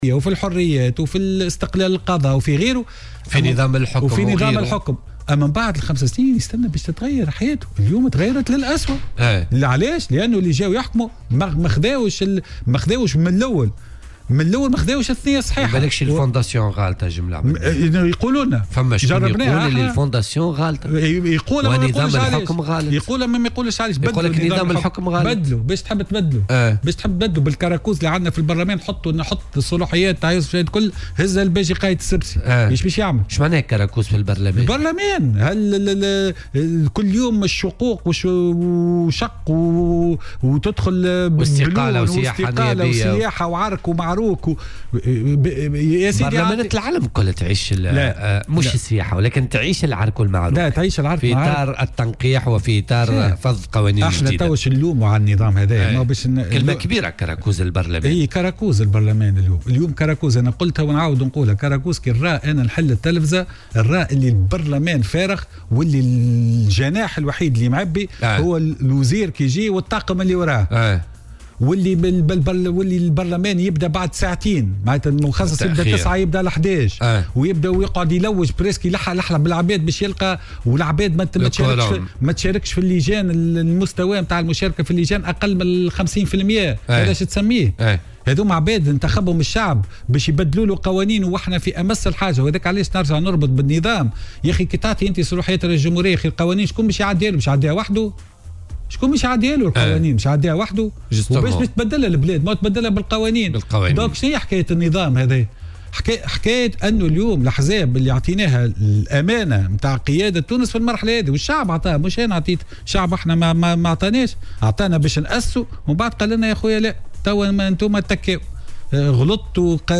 وقال ضيف "بوليتيكا" على "الجوهرة اف أم" إن ما يحصل اليوم في البرلمان يحمل على وصفه بـ "الكاركوز"، مستنكرا الفوضى بالبرلمان ولامبالاة النواب وعدم احترامهم ارادة الشعب في اشارة الى معركة الشقوق بين الأحزاب والسياحة الحزبية والتغّيب المتكرر للنواب وبعدد كبير فيما ضاعت في هذه الأثناء البلاد، بحسب تعبيره، معربا عن أمله في أن يحسن الناخب الاختيار في الانتخابات المقبلة.